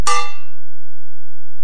Sound production: Swords Clank 1
Two swords clank together during sword fight
Product Info: 48k 24bit Stereo
Try preview above (pink tone added for copyright).